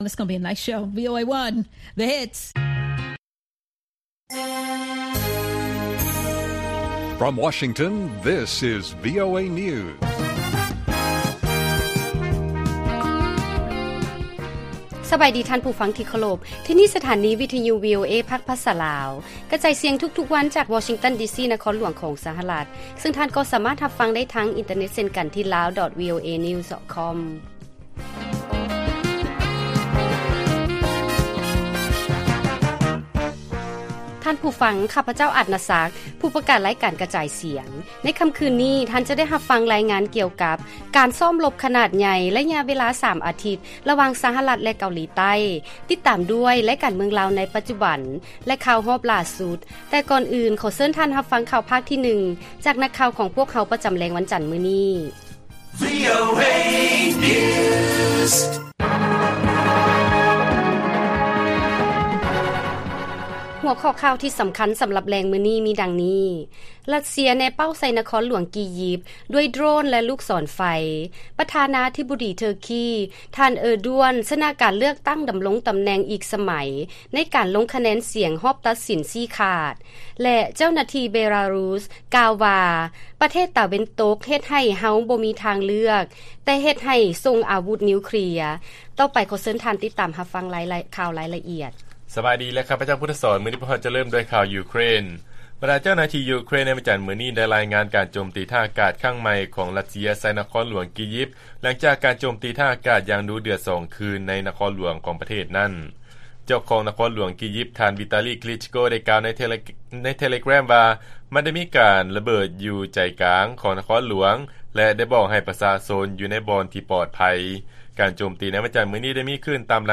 ລາຍການກະຈາຍສຽງຂອງວີໂອເອ ລາວ: ຣັດເຊຍ ແນເປົ້າໃສ່ນະຄອນຫຼວງ ກີຢິບ ດ້ວຍໂດຣນ ແລະ ລູກສອນໄຟ